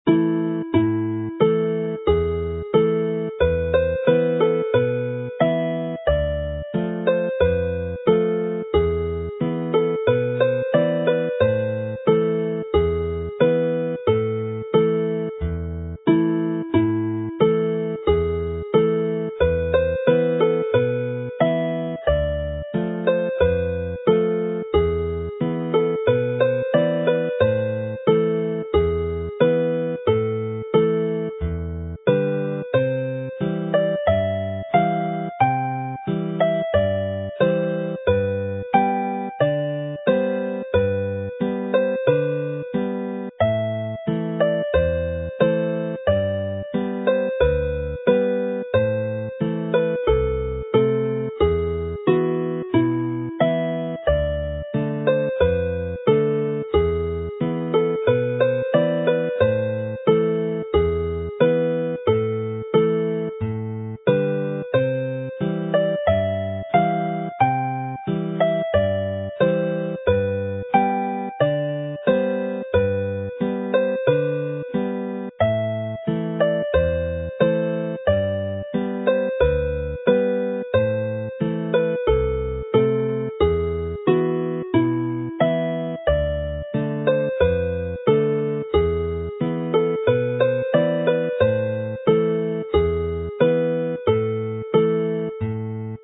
araf / slow
is a lovely, haunting melody in the Welsh minor mode.